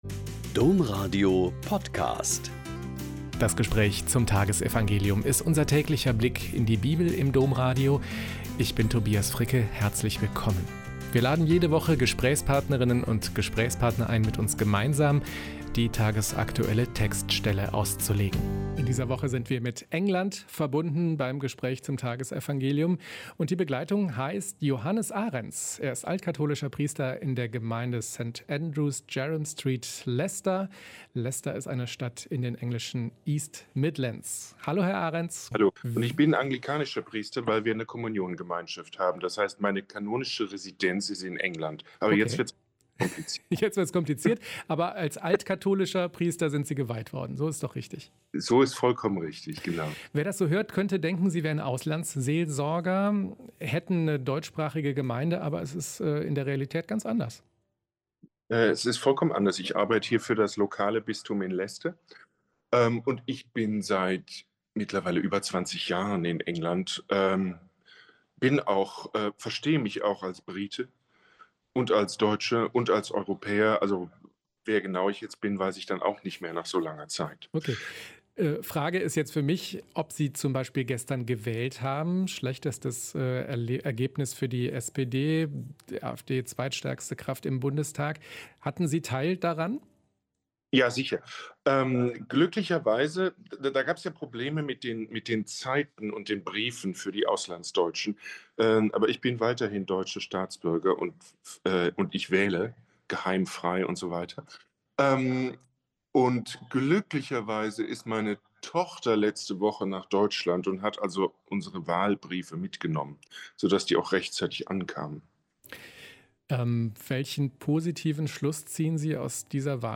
Joh 15,9-17 - Gespräch